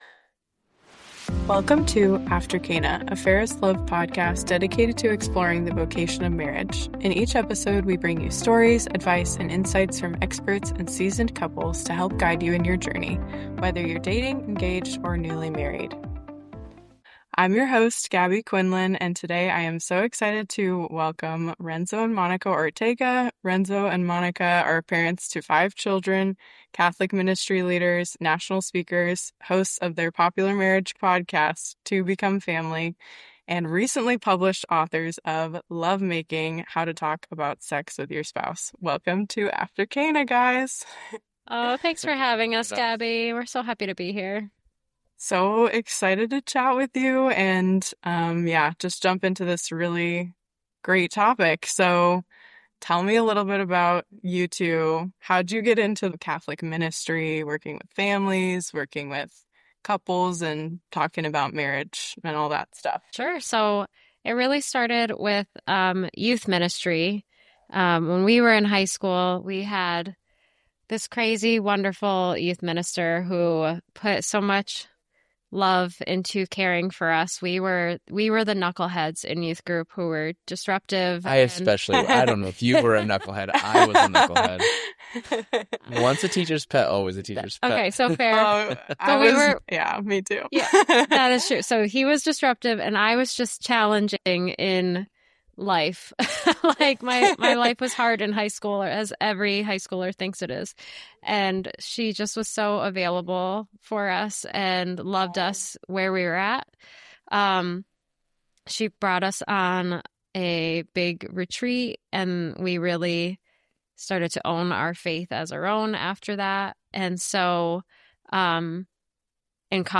This engaging two-part interview is a genuine and fun conversation about the beautiful complexities of married life.